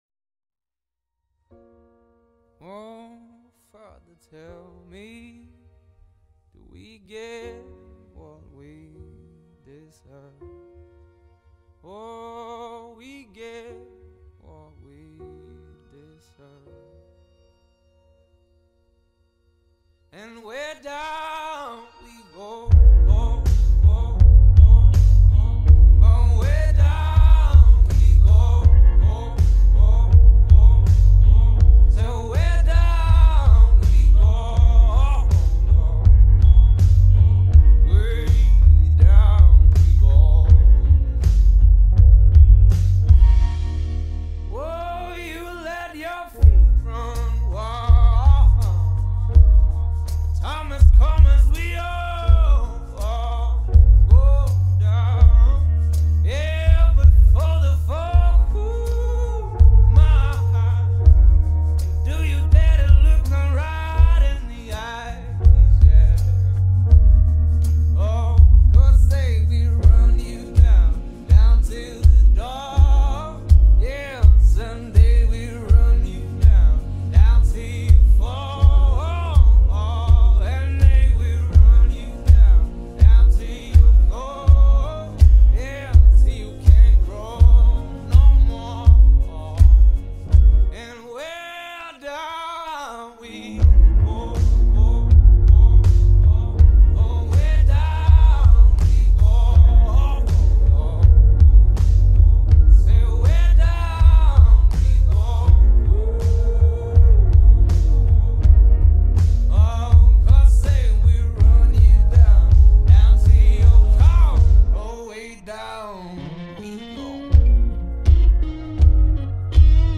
ریمیکس تقویت بیس قوی